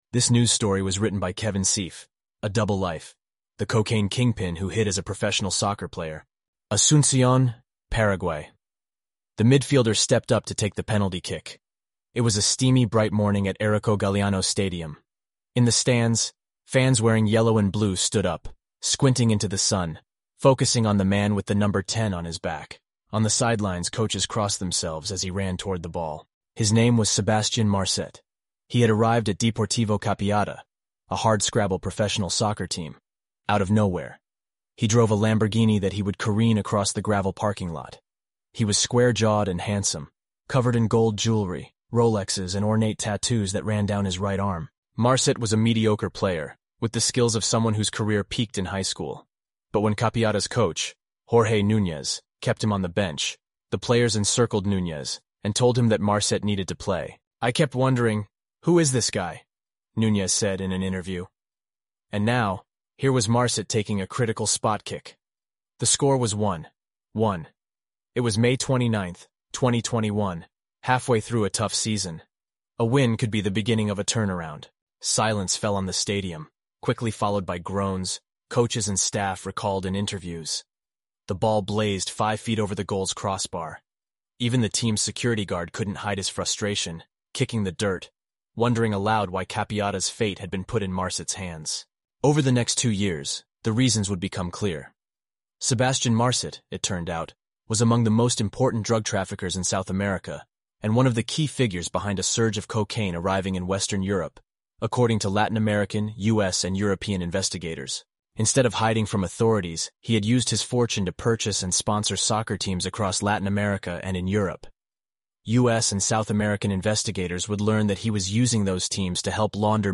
eleven-labs_en-US_Josh_standard_audio.mp3